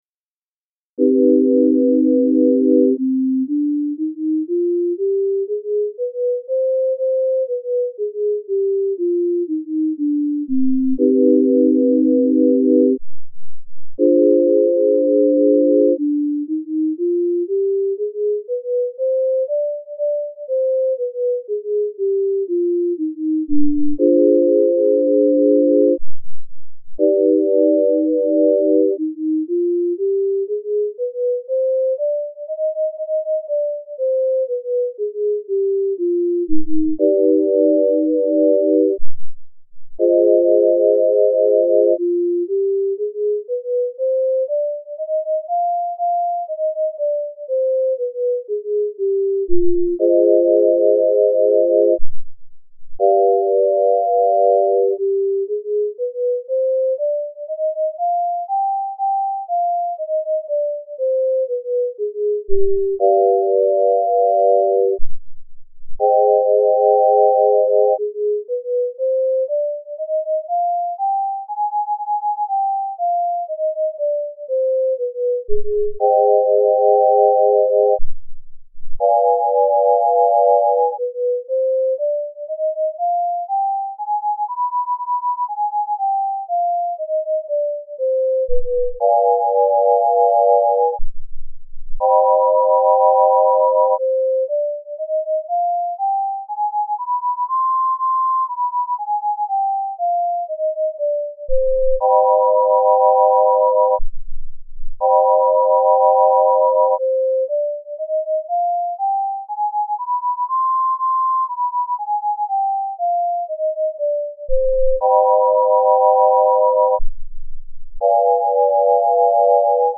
C-Harmonic Minor Scale - Left Ear Tempered - Right Ear Just
music02_012_Harmonics_Minor_Temp_Just.mp3